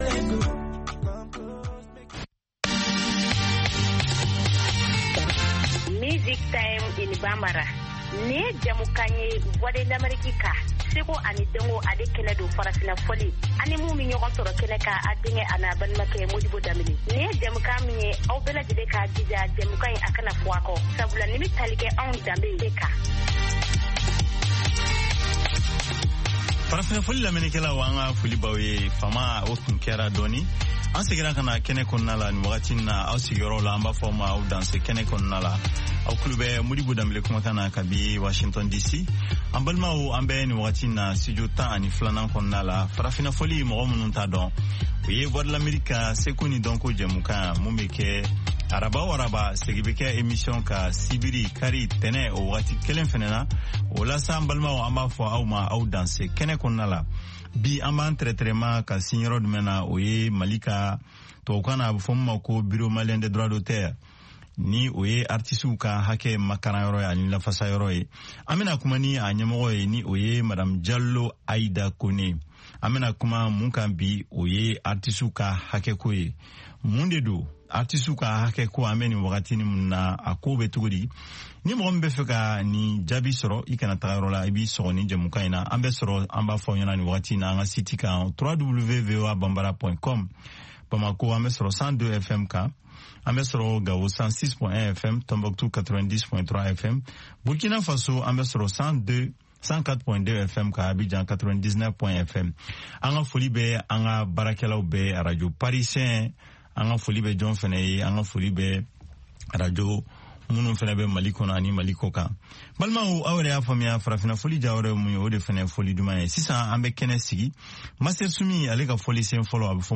Bulletin d’information de 17 heures
Bienvenu dans ce bulletin d’information de VOA Afrique.